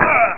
home *** CD-ROM | disk | FTP | other *** search / Horror Sensation / HORROR.iso / sounds / iff / die9.snd ( .mp3 ) < prev next > Amiga 8-bit Sampled Voice | 1992-09-02 | 2KB | 1 channel | 5,063 sample rate | 0.06 seconds
die9.mp3